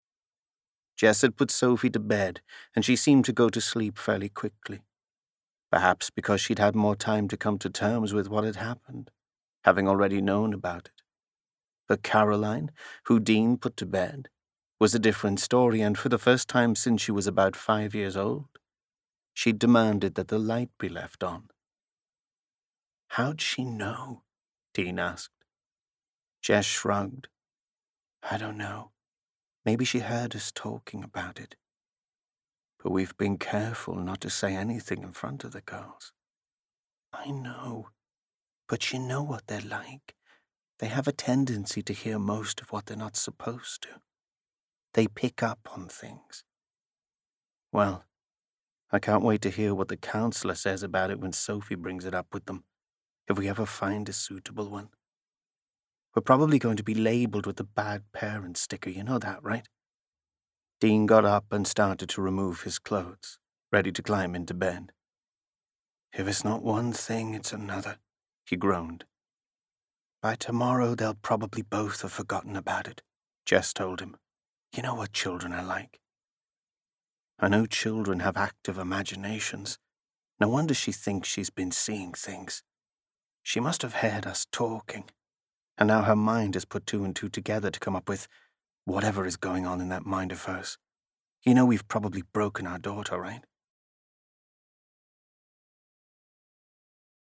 Audiobook Check
Trying my best to keep plugins etc to a minimum.